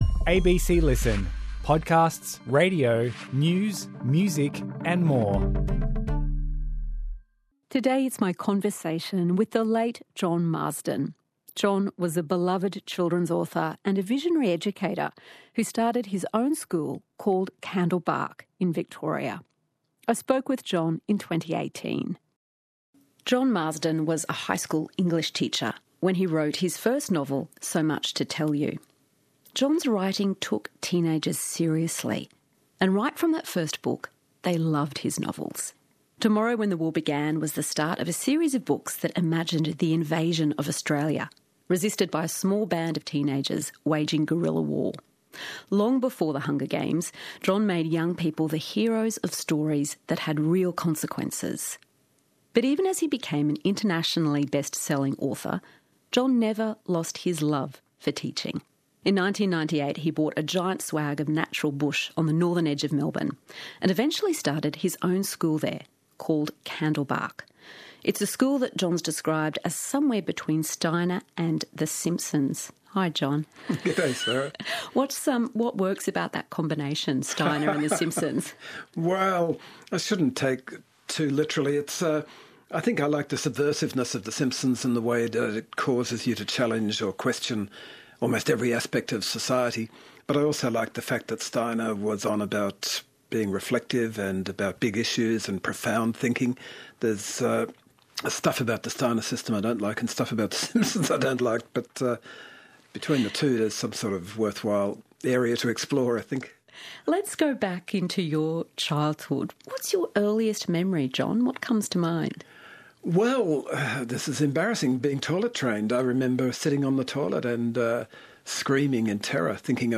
In 2024, John Marsden died at the age of 74, this interview was recorded in 2018.